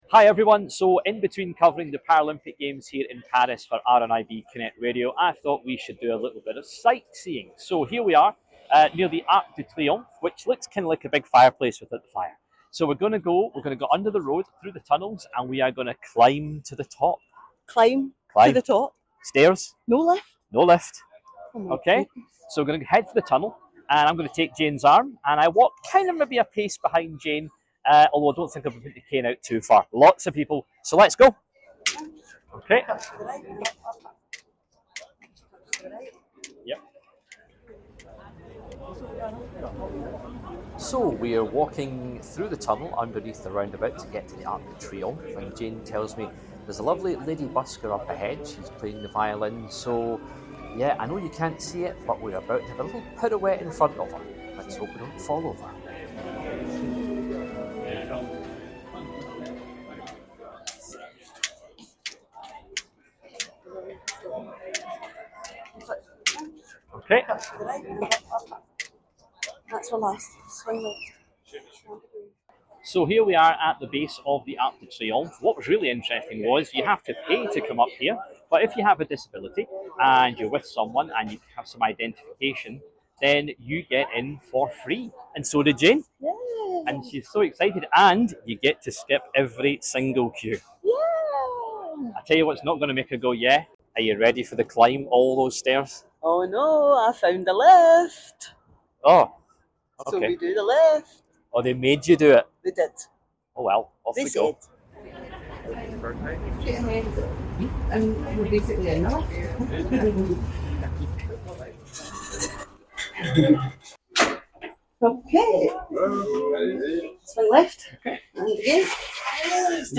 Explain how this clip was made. Our team is in Paris right now reporting from the Paralympic Games, and whilst there they checked out one of the most famous landmarks in the city, Arc de Triomphe.